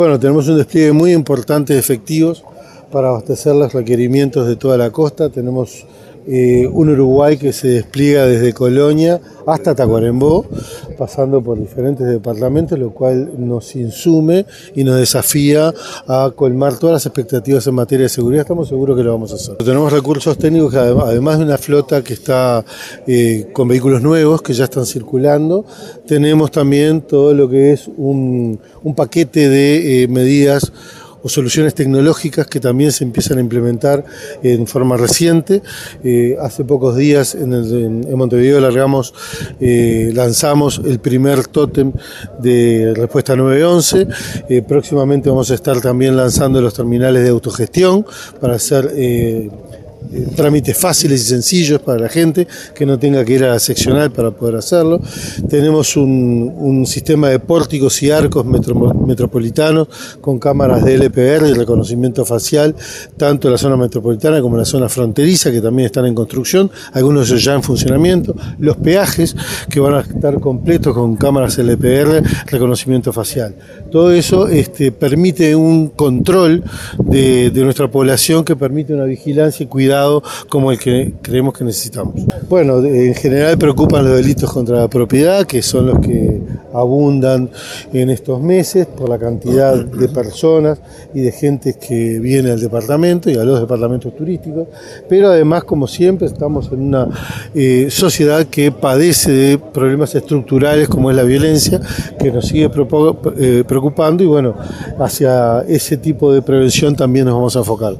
En un acto realizado al aire libre en la explanada de la Punta de Salinas, que marca el límite entre el Atlántico y el Río de la Plata, se lanzó oficialmente el operativo Verano Azul, el plan de seguridad para afrontar la temporada veraniega en dos de los departamentos que tienen más movimiento en esta época del año. El jerarca dio detalles del operativo y se refirió al sistema de cámaras de videovigilancia que funciona en Maldonado.